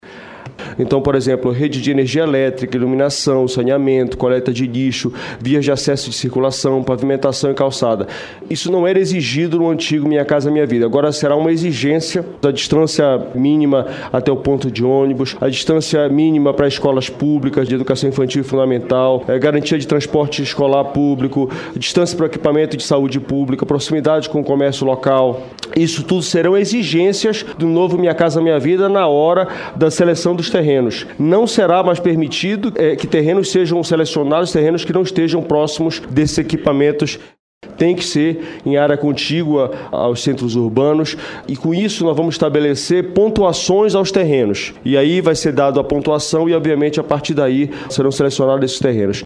As declarações de Jader Filho foram dadas durante audiência pública no Senado Federal, na última terça-feira (16).